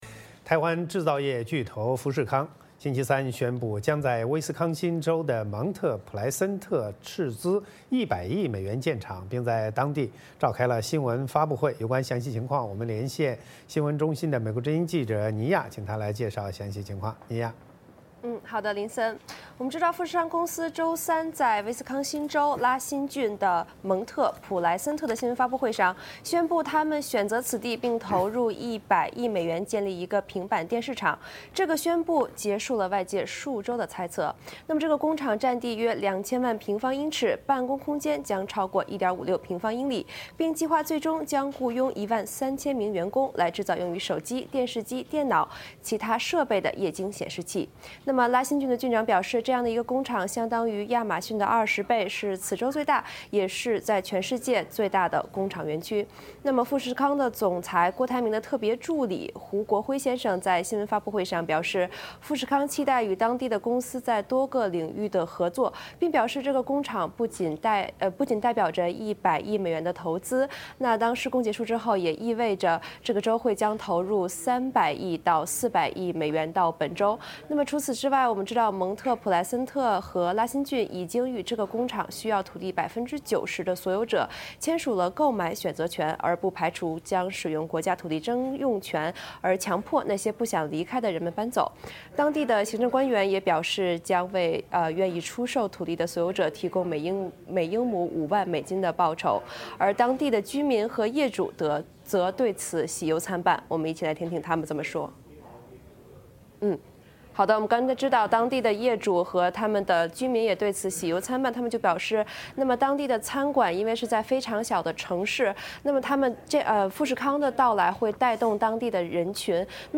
VOA连线：富士康宣布威斯康辛州厂址